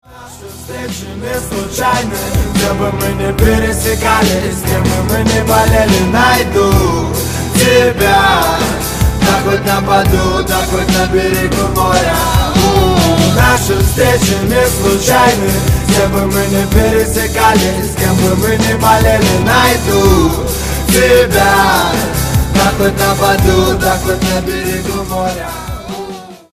• Качество: 128, Stereo
мужской вокал
громкие
пианино
медленные